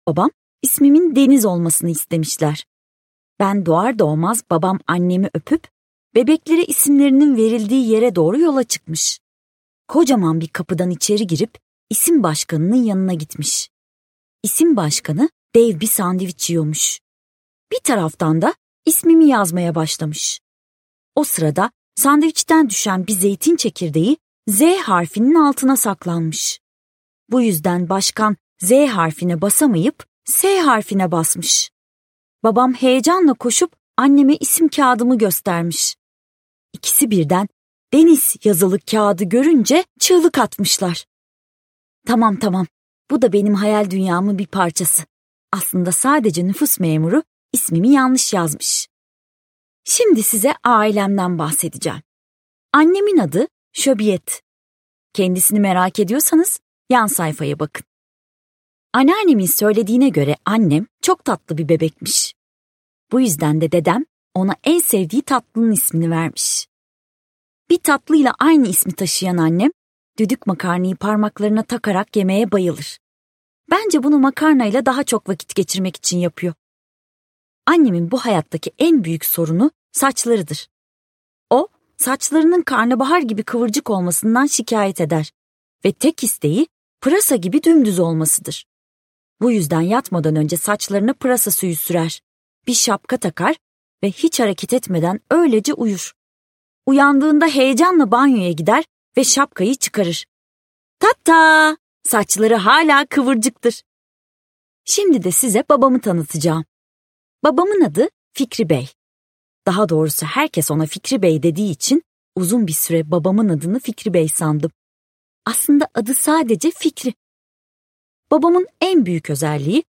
Denis Çetrefilli - Seslenen Kitap